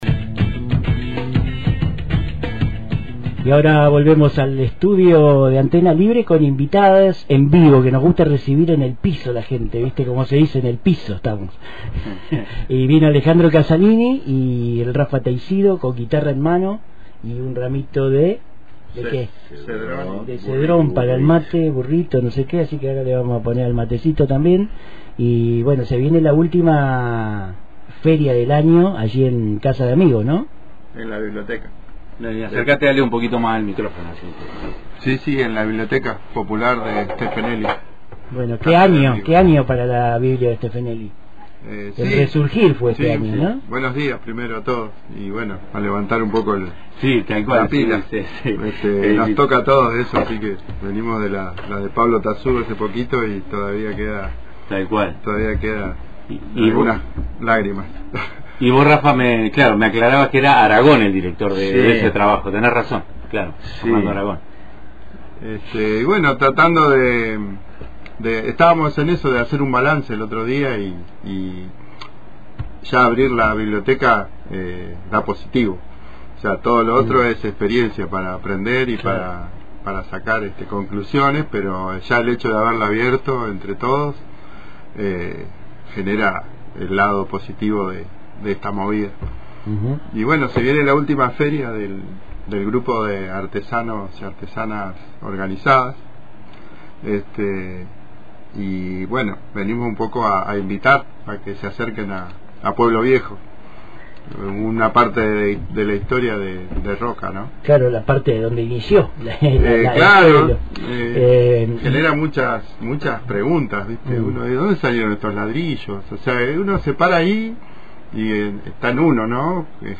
En los estudios de Antena Libre